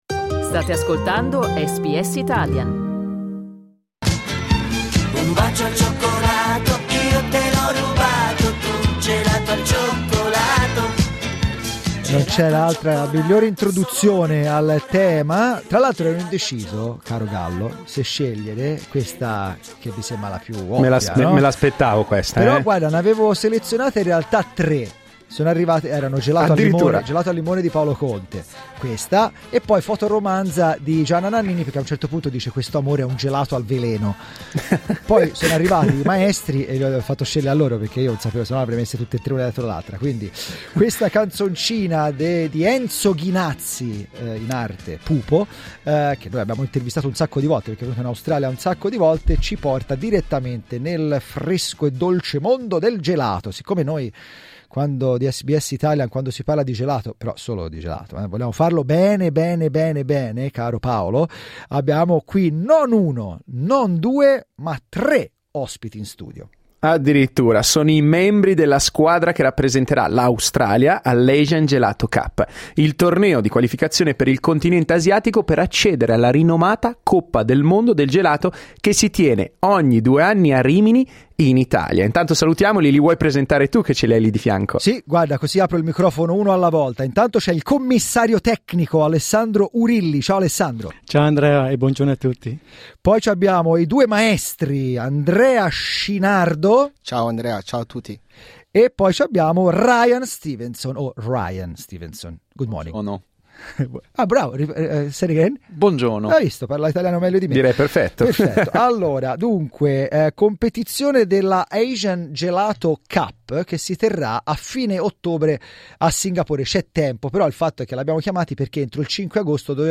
Ascolta l'intervista al Team Australia cliccando sul tasto '"play" in alto